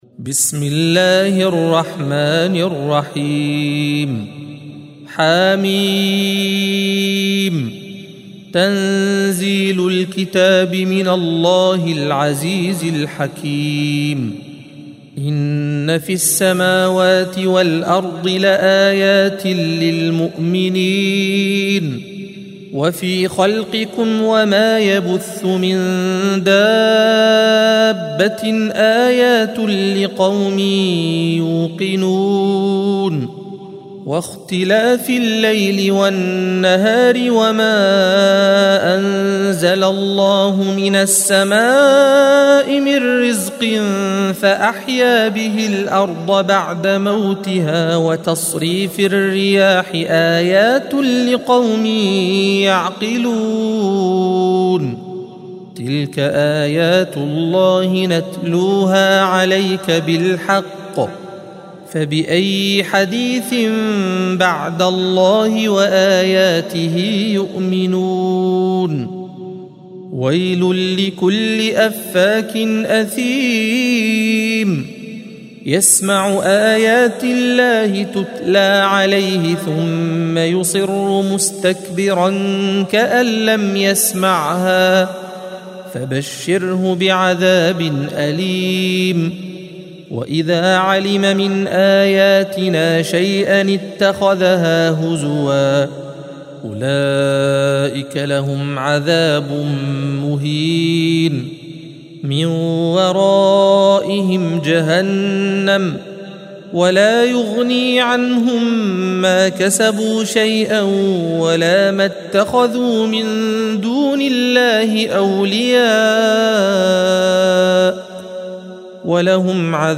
القارئ